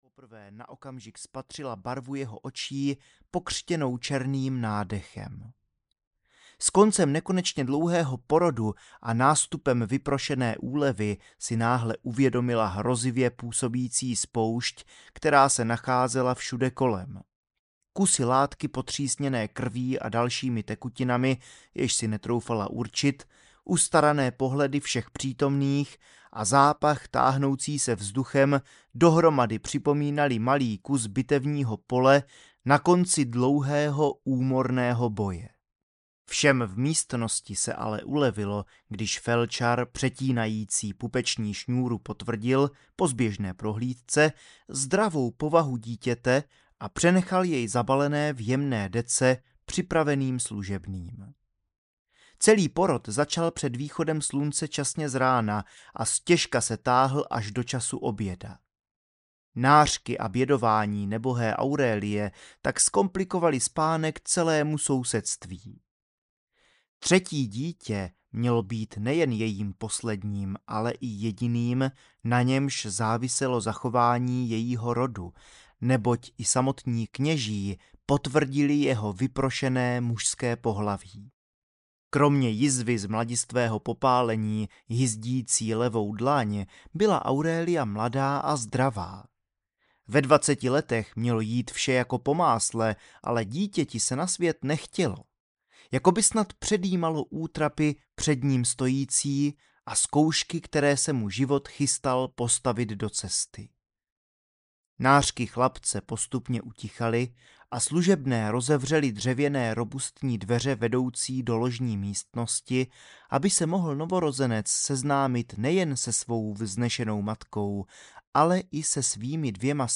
Sněm první: NEMETHON audiokniha
Ukázka z knihy